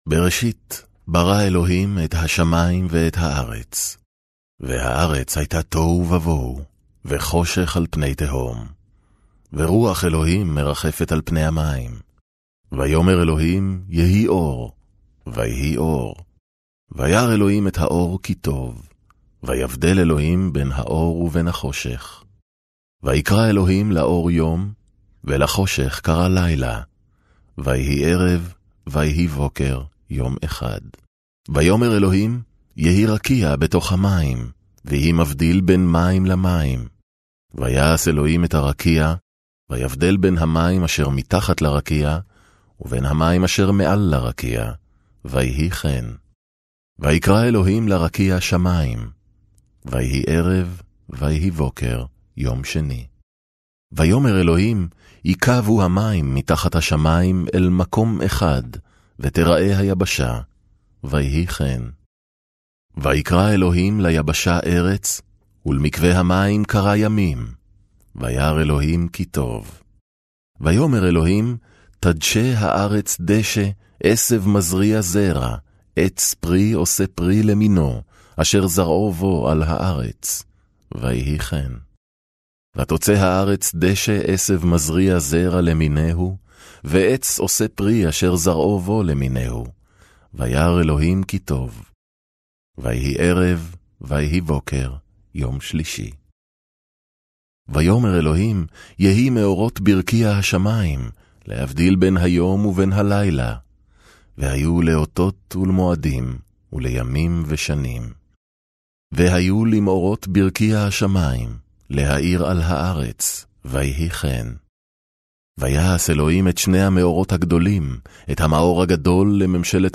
Very experienced Hebrew Voice over artist from Israel. own studio. provide production facilities.
Sprechprobe: Werbung (Muttersprache):